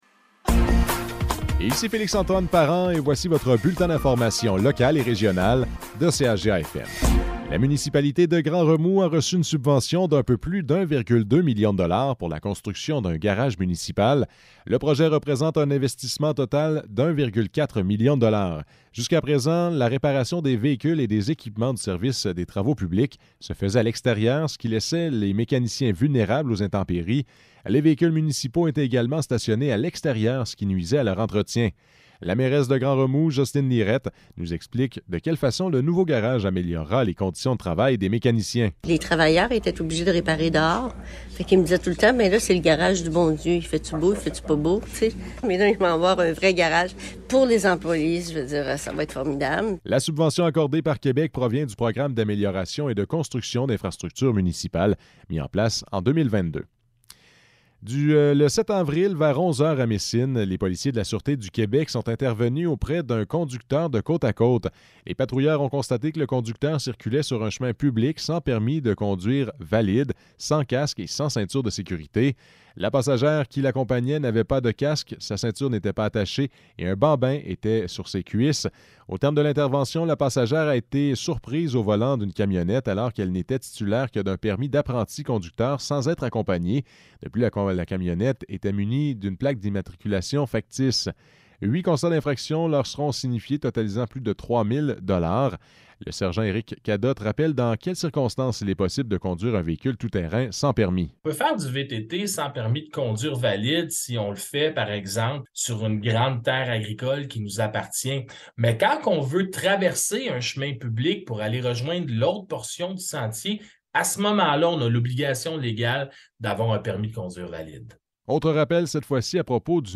Nouvelles locales - 13 avril 2023 - 12 h